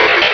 Cri de Gobou dans Pokémon Rubis et Saphir.